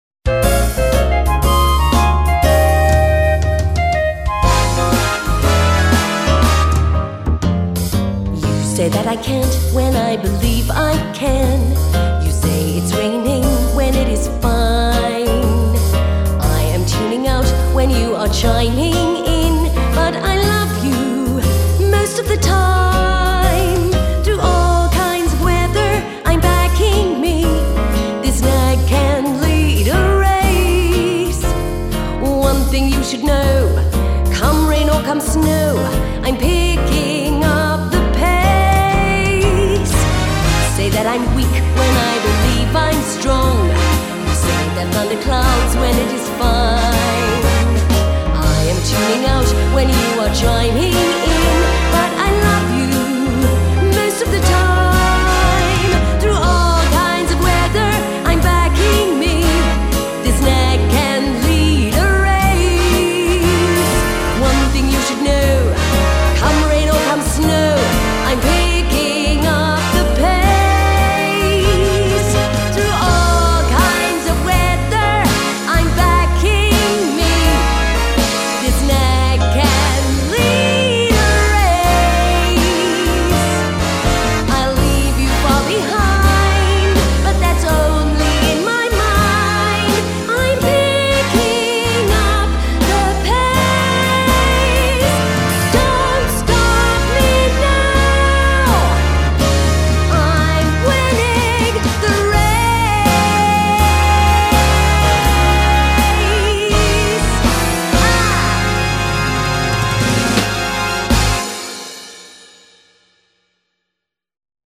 Here is a fun little song for you, it was written as a parody of the war within my mind.
Mixed and Mastered by:  Big Tone Productions
Picking-up-the-pacewith-vocal-M320Kbps.mp3